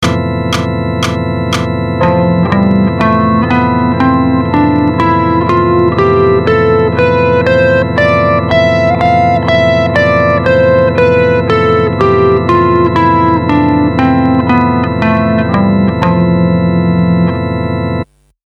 Lydian Mode
It has a very open sound to it and works over the exotic Major7#11 chord.
f_lydian.mp3